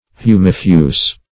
Search Result for " humifuse" : The Collaborative International Dictionary of English v.0.48: Humifuse \Hu"mi*fuse\, a. [L. humus ground + fusus, p. p. of fundere to spread.]